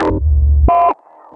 Abstract Rhythm 42.wav